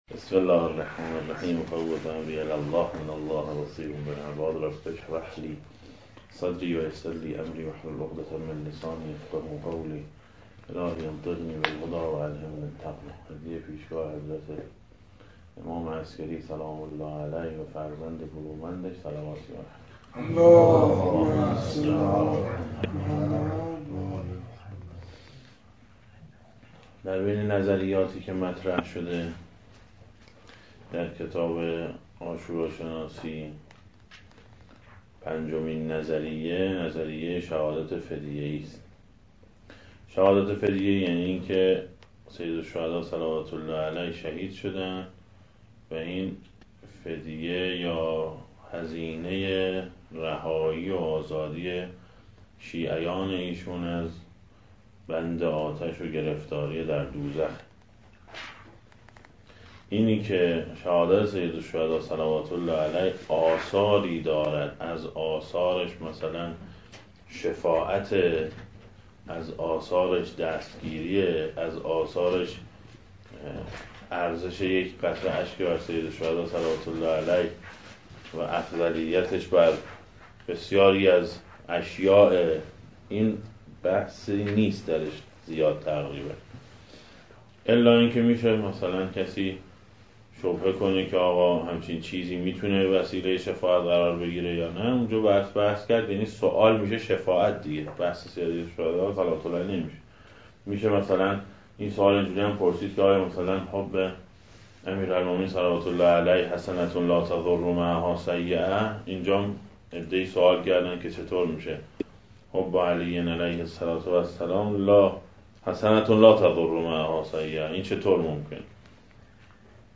جهت دریافت صوت این کلاس، اینجا کلیک نمایید.